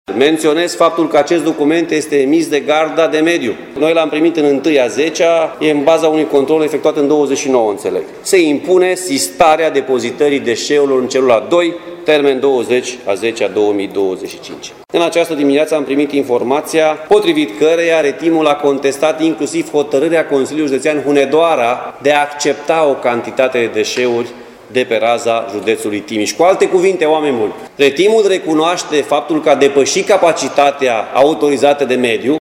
Președintele Consiliului Județean Timiș, Alfred Simonis, afirmă că măsura este contestată de Retim, operatorul deponeului: